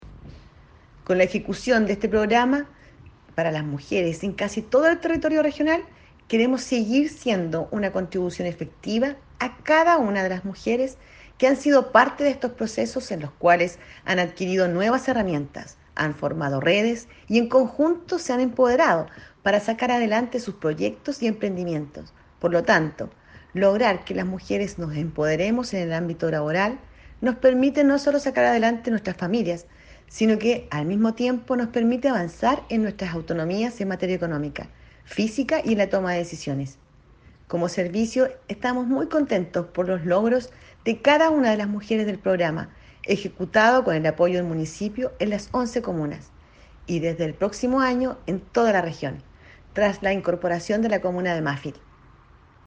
REPORTAJE-CUÑA-05-DIRECTORA-SERNAMEG-ÁREA-MYT-Mujeres-Jefas-de-Hogar.mp3